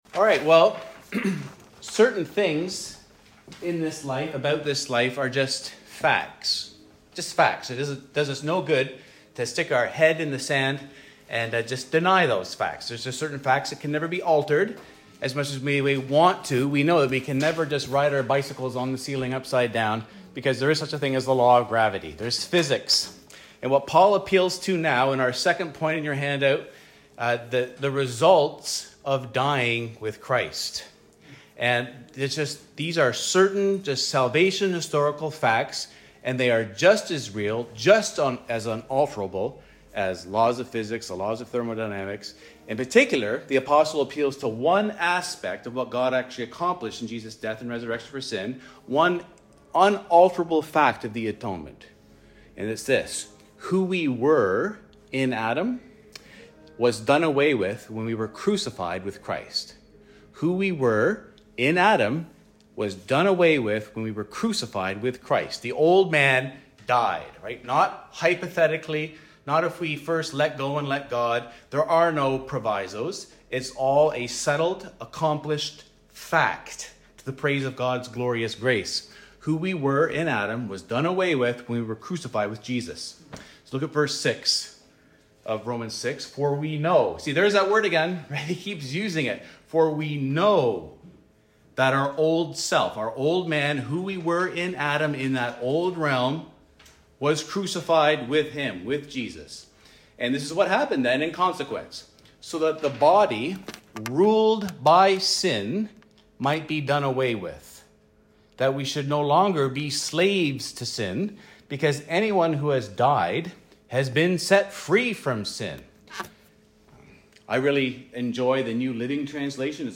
The sermons of Mount Pleasant Baptist Church in Toronto, Ontario.